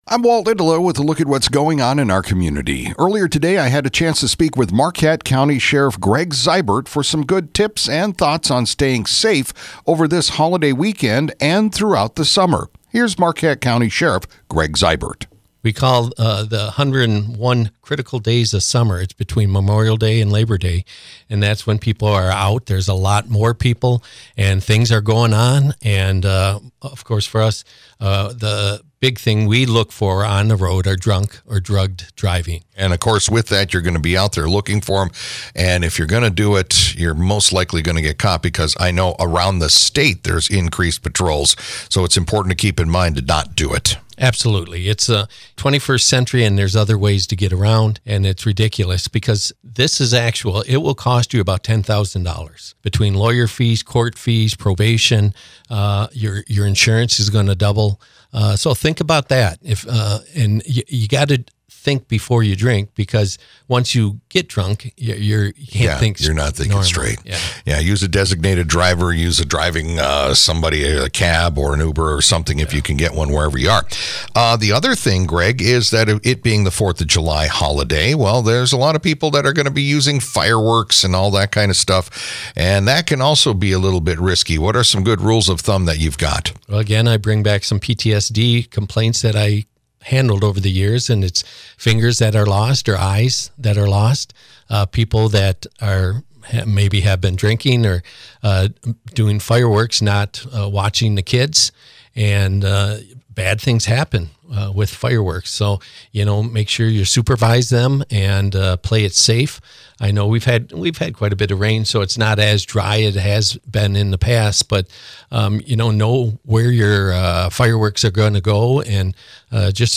Marquette County Sheriff Greg Zyburt